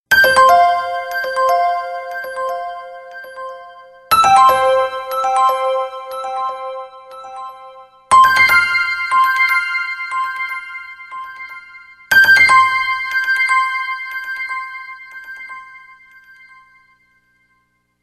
Alarm_Classic1.ogg